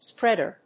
/ˈsprɛdɝ(米国英語), ˈspredɜ:(英国英語)/
アクセント・音節spréad・er